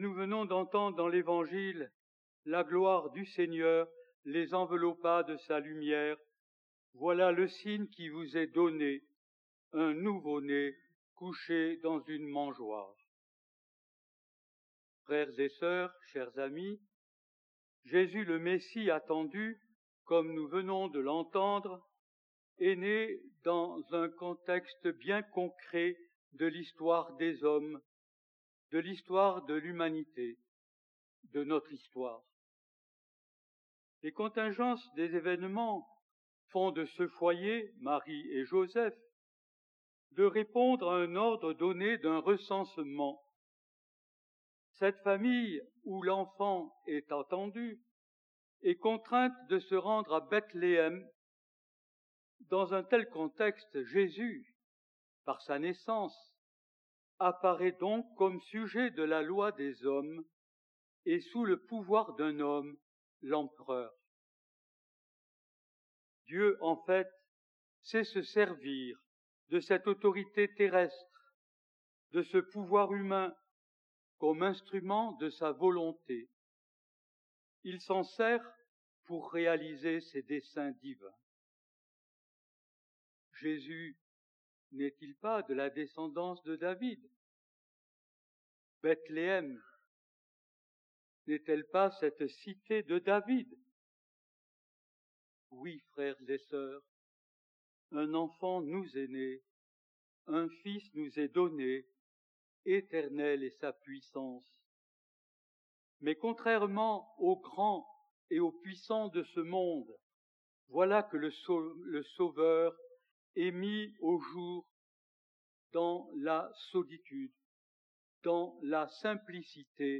Homélie pour la messe de la nuit de Noël